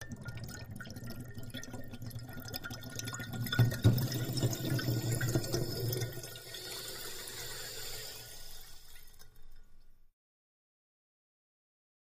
Seltzer Bottle
Water, Bubbles; Dramatic Bubbles Burble And Bang Ending In Fizz ( Water Into Glass From Seltzer Bottle )